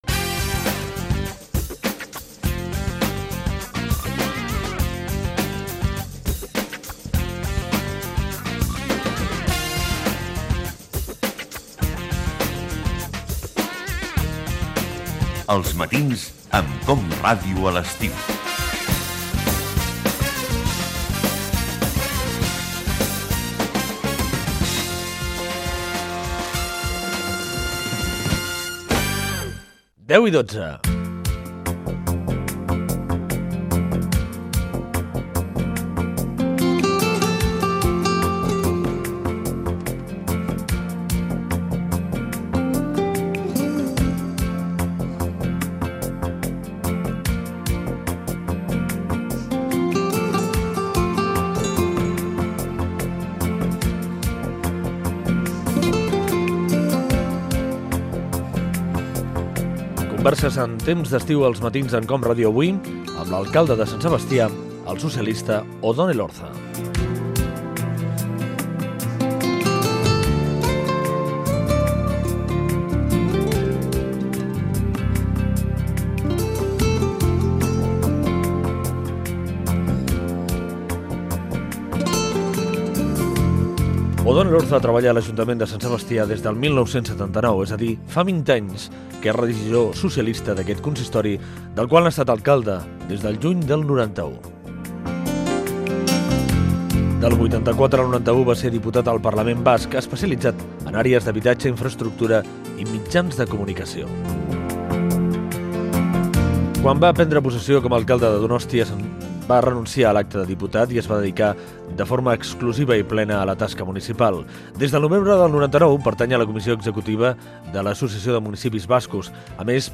eb4f5a53de7a9f73fbbfff19a6ccfae9ad3f2848.mp3 Títol COM Ràdio Emissora COM Ràdio Barcelona Cadena COM Ràdio Titularitat Pública nacional Nom programa Els matins amb COM Ràdio Descripció Indicatiu del programa. Hora, fragment d'una entrevista a l'alcalde de Sant Sebastià, Odón Elorza